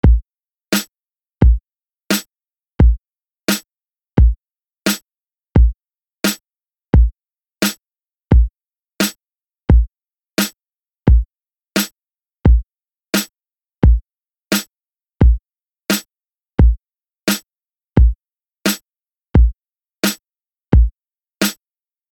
Try to move your hand or head back and forth at an even speed so that the forward position is landing directly on the two snares:
Kick and Snare
You see, when you were moving your body at an even speed, not only was your forward position landing on the 2nd and 4th beats, but your backwards position was landing on the 1st and 3rd beats.
EASY-EAR-TRAINING-KICK-AND-SNARE.mp3